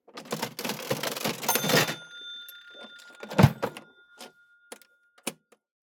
Antique-Cash-Register_07.ogg